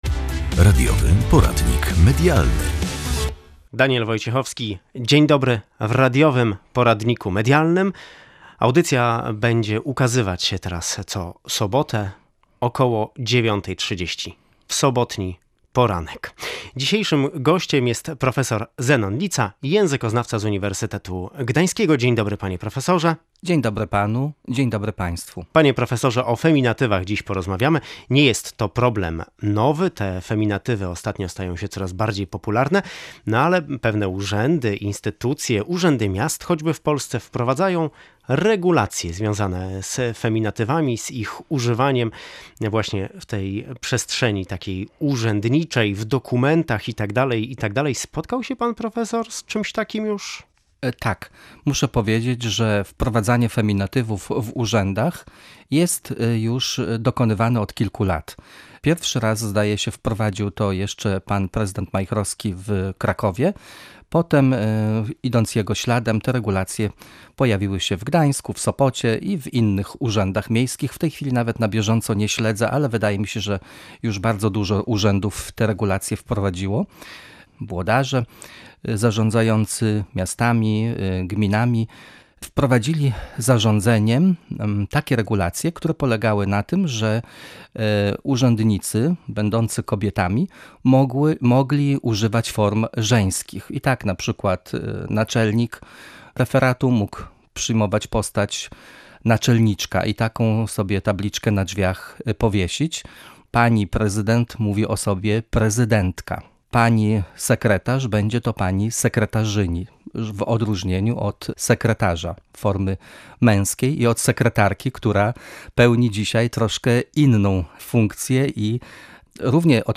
Naczelniczka, prezydentka, sekretarzyni. Rozmowa z językoznawcą o feminatywach w urzędach